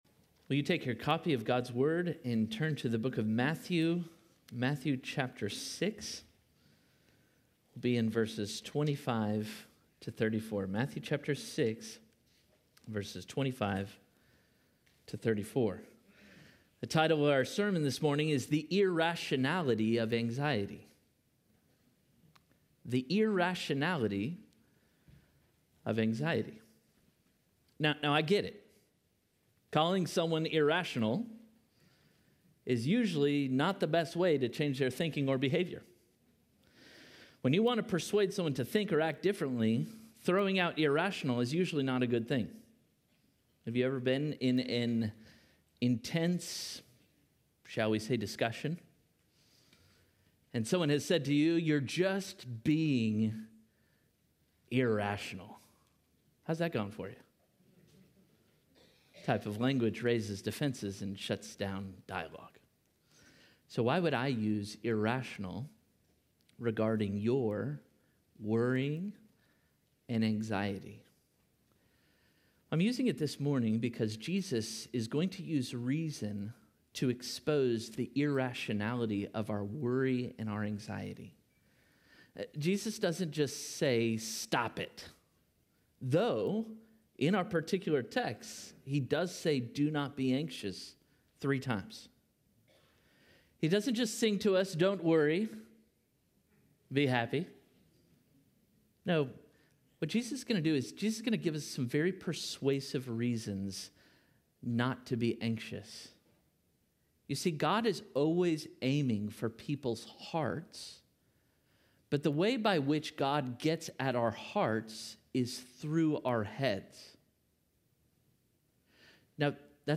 Parkway Sermons